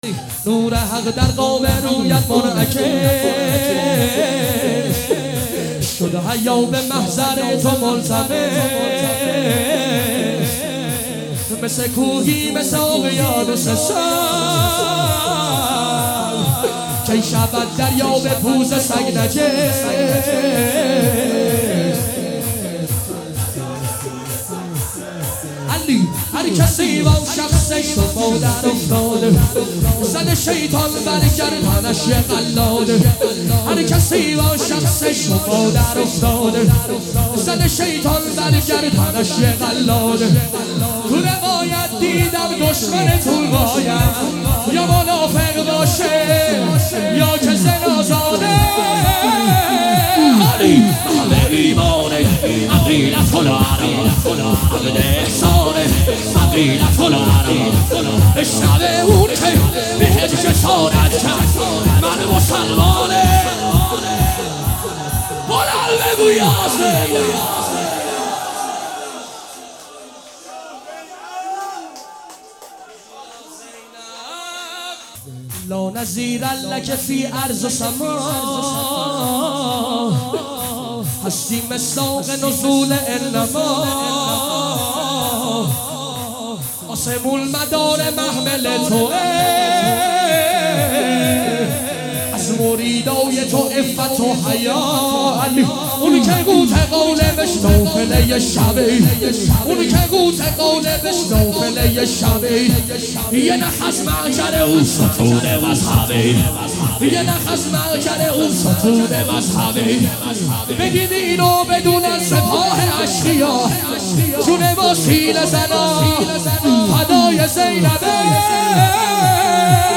شهادت حضرت مسلم ابن عقیل (ع) | هیئت عزاداران حضرت مسلم ابن عقیل (ع)